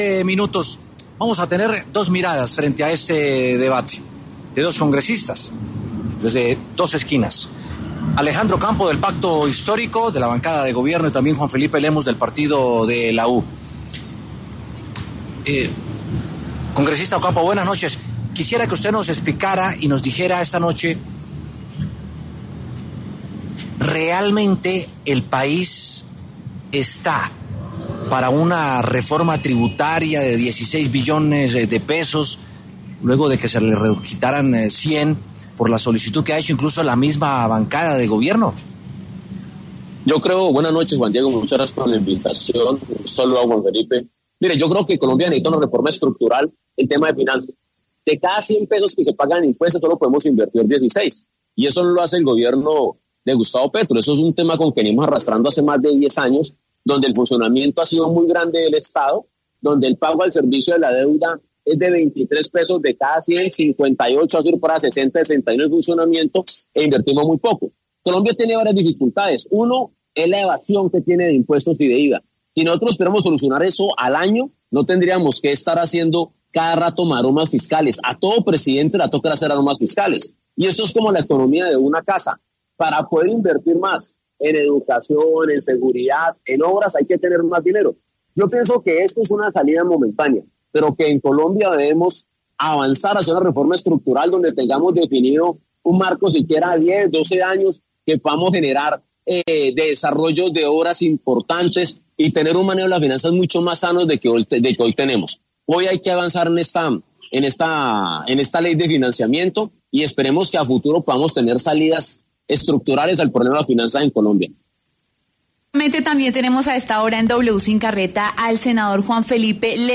Alejandro Ocampo del Pacto Histórico y Juan Felipe Lemus del Partido de La U, debatieron sobre el monto del presupuesto luego de que la votación se levantara por falta de quorum.